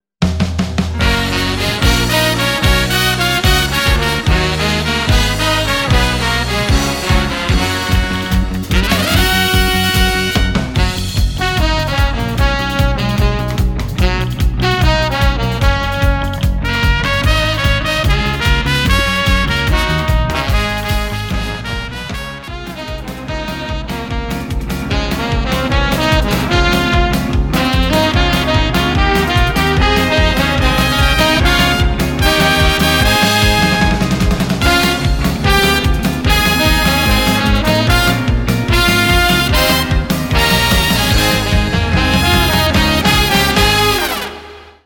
分類 駈足148 時間 2分49秒
編成内容 大太鼓、中太鼓、小太鼓、シンバル、トリオ 作成No 304